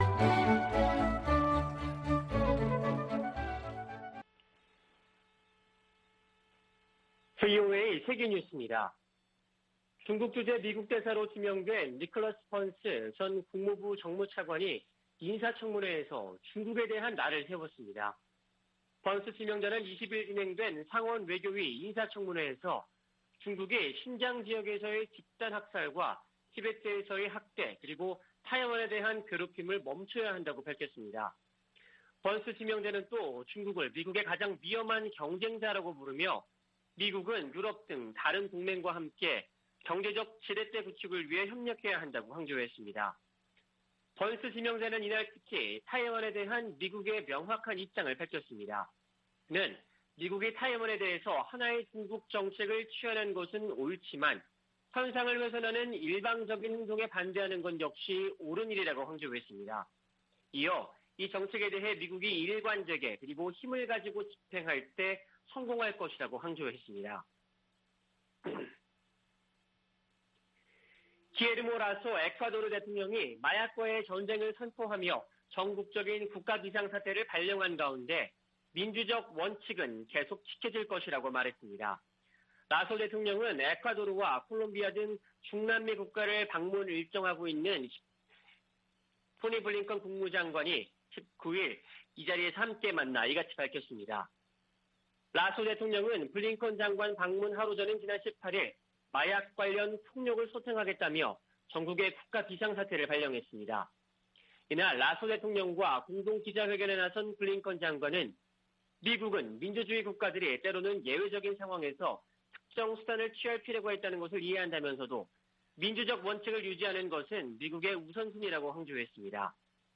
VOA 한국어 아침 뉴스 프로그램 '워싱턴 뉴스 광장' 2021년 10월 21일 방송입니다. 미국 정부는 북한의 탄도미사일 발사에 우려하며 조속히 대화에 나설 것을 촉구했습니다. 유엔은 북한 미사일과 관련, 국제 의무를 준수하고 외교노력을 재개하라고 촉구했습니다.